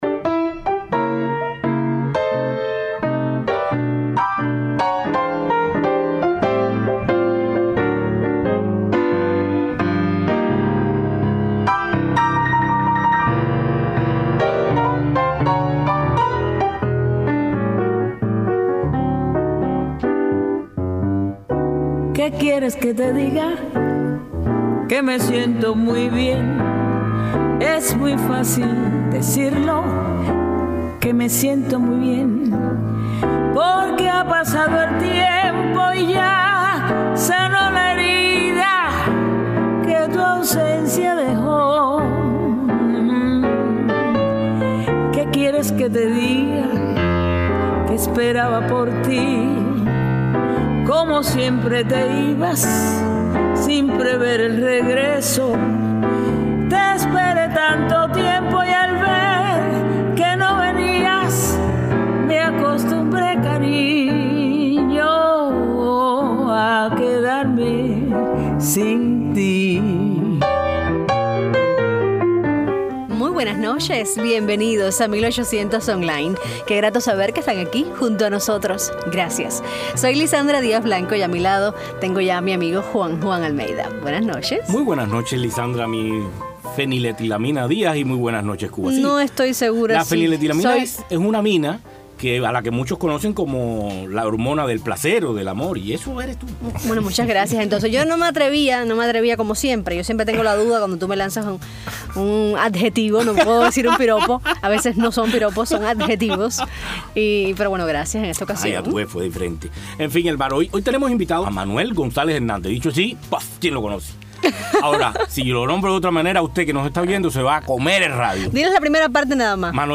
Entrevista a la cantante cubana Daymé Arocena sobre su nuevo álbum Al-Kemi